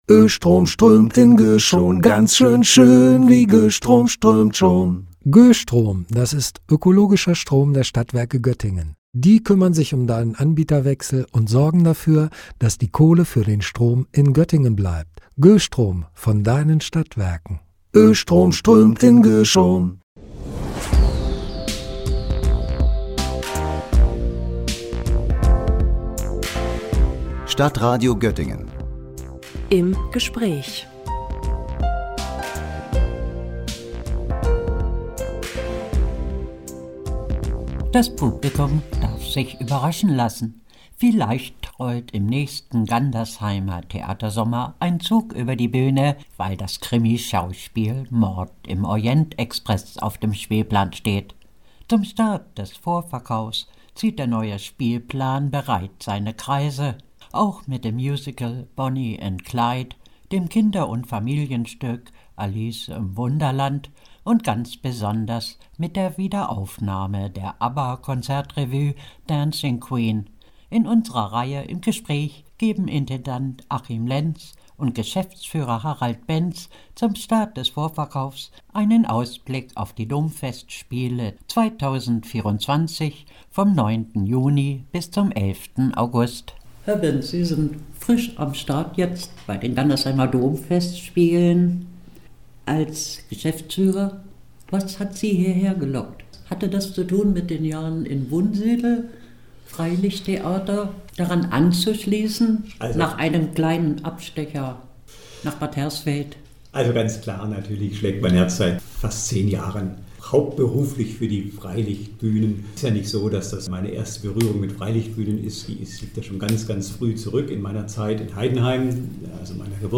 Der neue Spielplan der Gandersheimer Domfestspiele – Gespräch